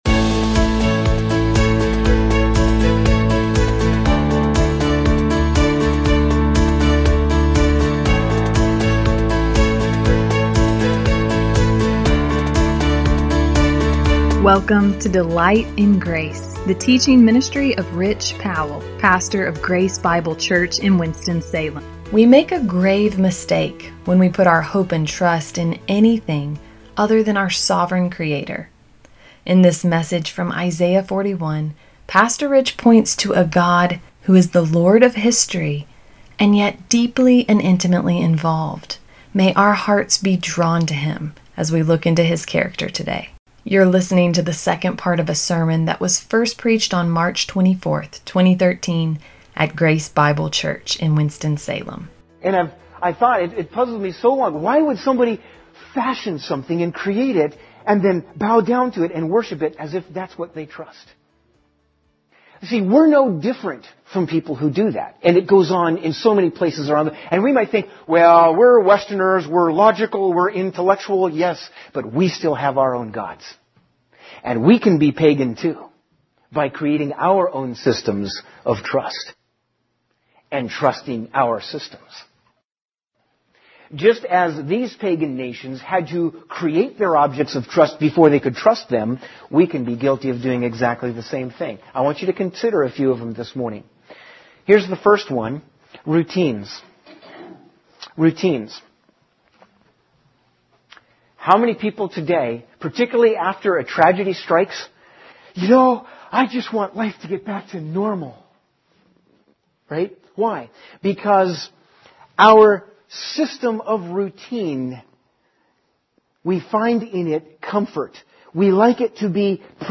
We make a grave mistake when we put our hope and trust in anything other than our sovereign Creator. In this message from Isaiah 41